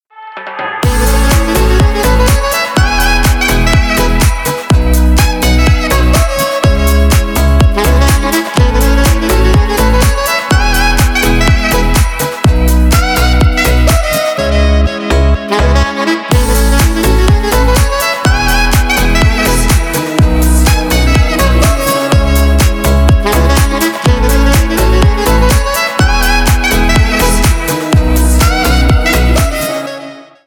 Танцевальные
клубные # без слов